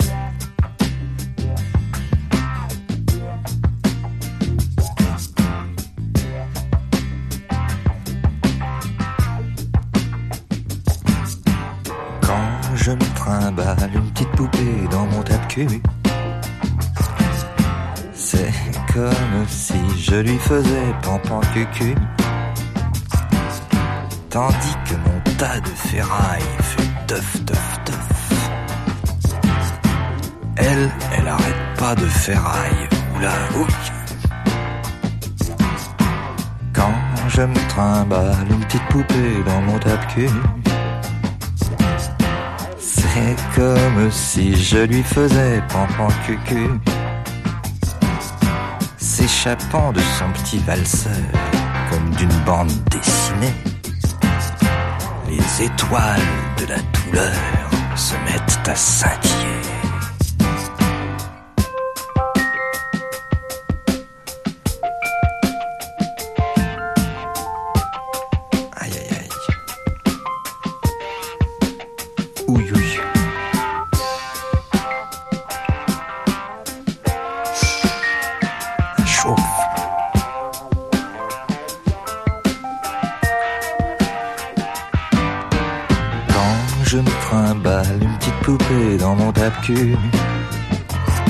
ファンキーな